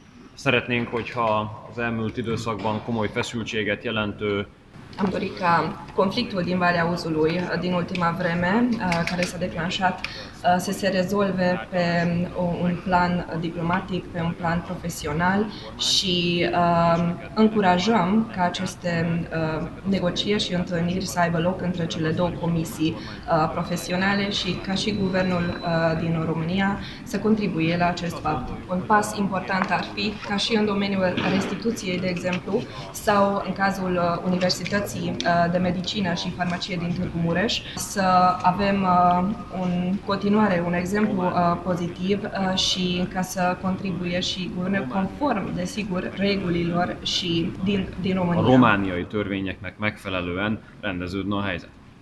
Ministrul Comerțului Exterior și al Afacerilor Externe din Ungaria, Szijjártó Péter, s-a aflat, astăzi, la Arad, unde a avut o întrevedere cu președintele UDMR, Kelemen Hunor, în incinta Aeroportului Internațional Arad.